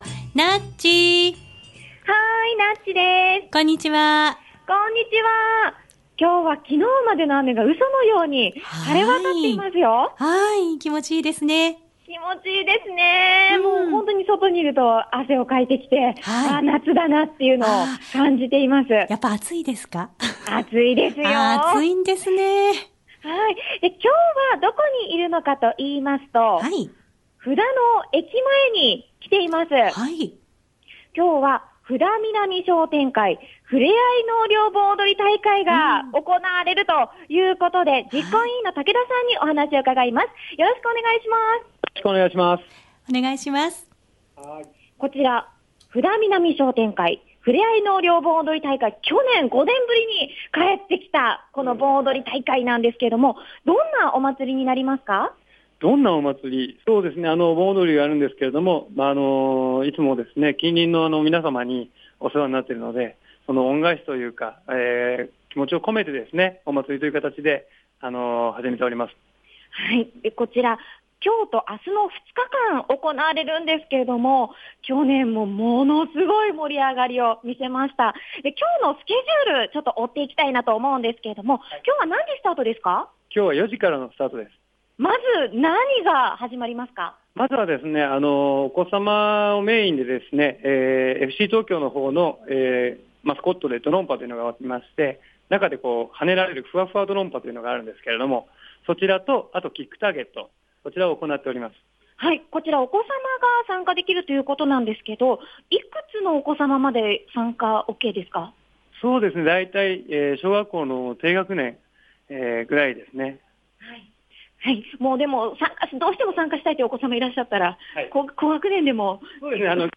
☆★布田南商店会 納涼盆踊り大会★☆ 日時：平成２８年８月２５日（木）１６：００～２１：００ ８月２６日（金）１７：００～２１：００ 場所：布田駅前広場特設会場 雨天中止・小雨決行 布田南商店会 納涼盆踊り大会 今日は布田南商店会 納涼盆踊り大会が行われるということで、 布田駅前ロータリーからお届けしました！